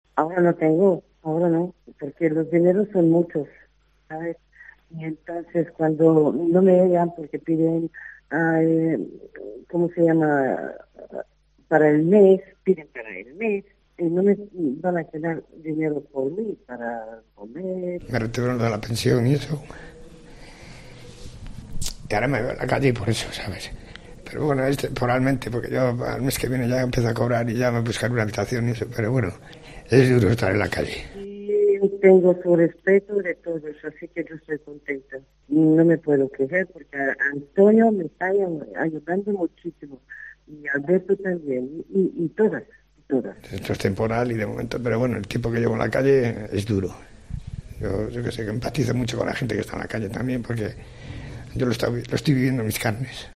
El duro testimonio de dos personas sin hogar en Valladolid: "Lo estoy viviendo en mis carnes"